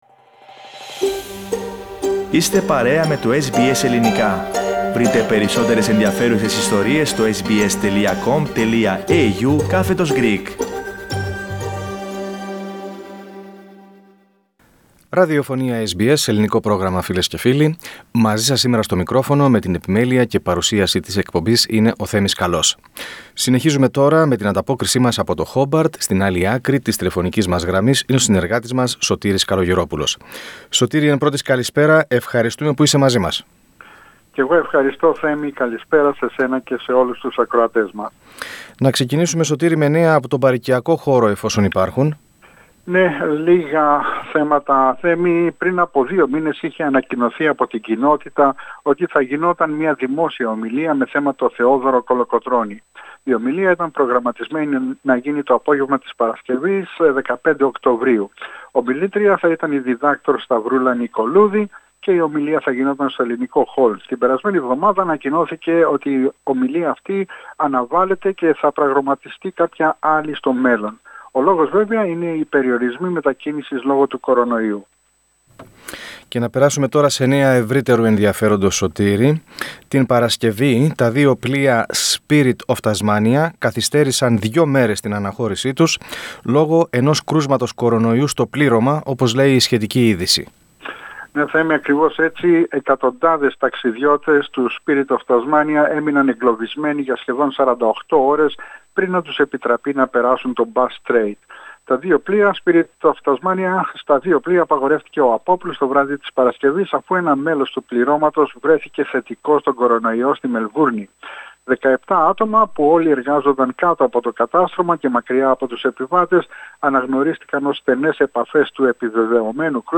Πατήστε PLAY πάνω στην εικόνα για να ακούσετε την ανταπόκριση του SBS Greek/SBS Ελληνικά από την Τασμανία.